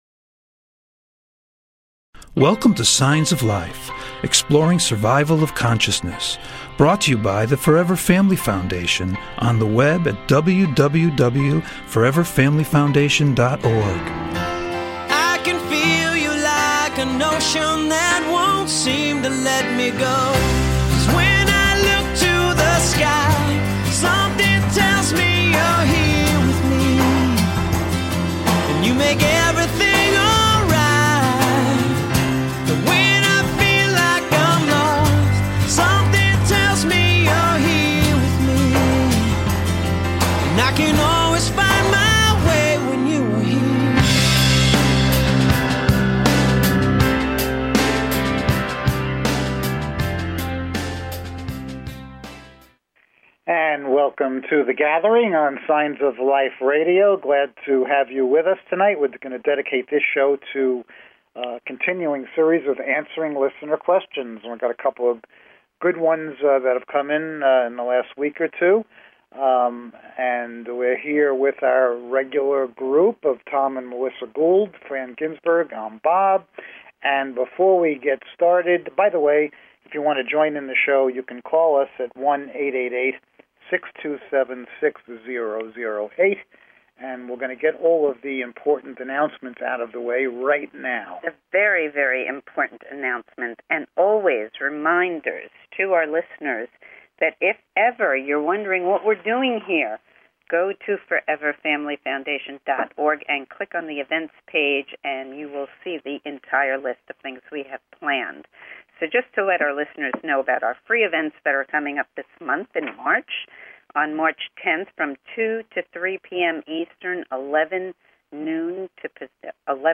Signs of Life Radio Show is a unique radio show dedicated to the exploration of Life After Death!
Call In or just listen to top Scientists, Mediums, and Researchers discuss their personal work in the field and answer your most perplexing questions. Topics will include: Mediumship, Near Death Experiences, Death Bed Visions, Reincarnation, Apparitions and Poltergeists, After Death Communication, ESP and Telepathy, Survival of Consciousness, and the list is endless!